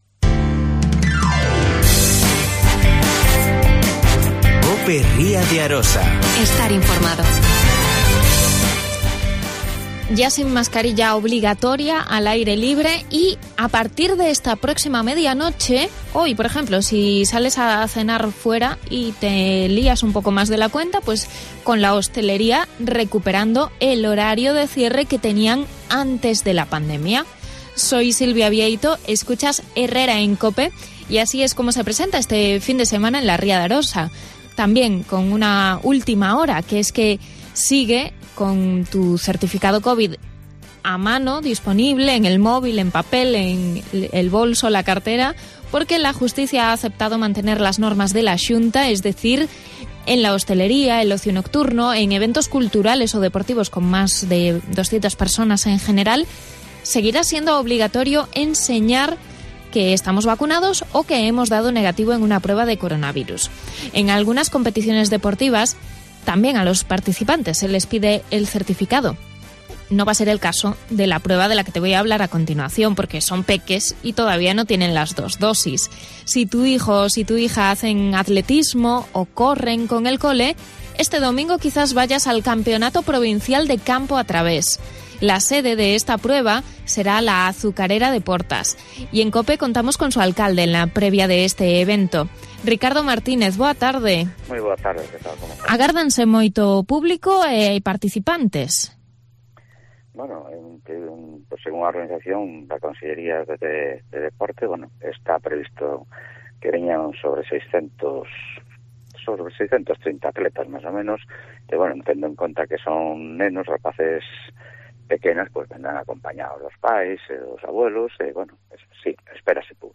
Alcalde de Portas.